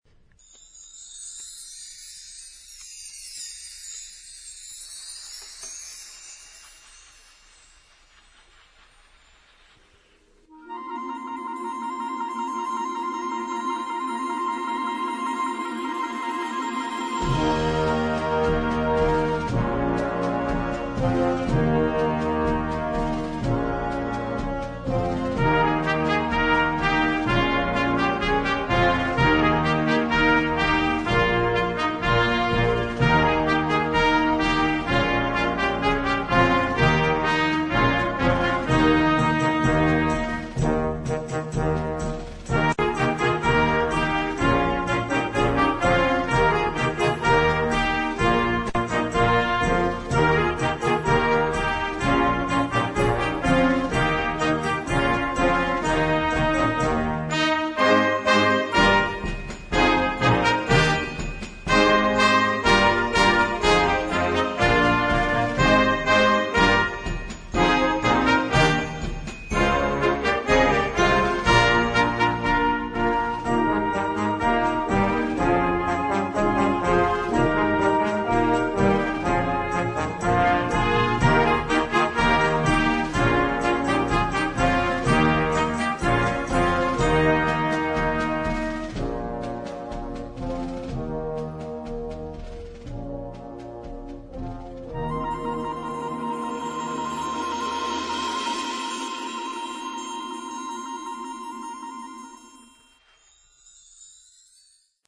Partitions pour orchestre d'harmonie des jeunes.